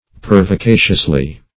Meaning of pervicaciously. pervicaciously synonyms, pronunciation, spelling and more from Free Dictionary.
-- Per`vi*ca"cious*ly , adv.
pervicaciously.mp3